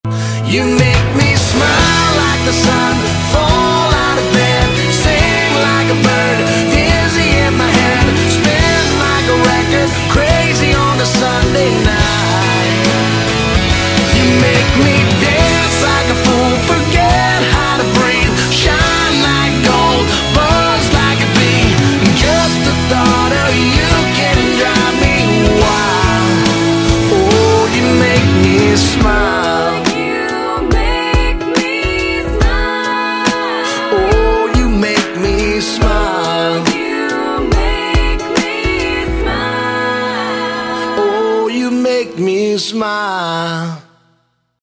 • Качество: 128, Stereo
мужской вокал
Pop Rock
country pop